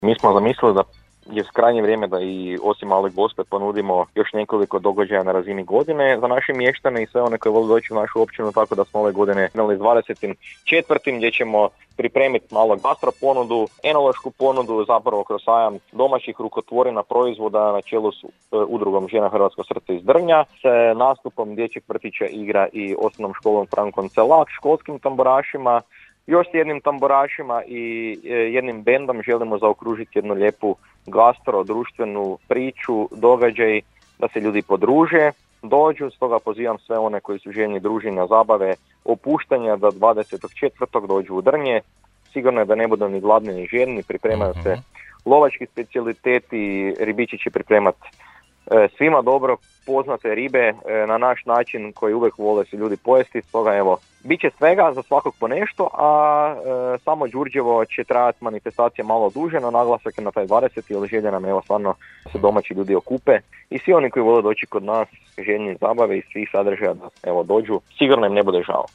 -najavio je za Podravski radio načelnik Općine Drnje, Petar Dombaj.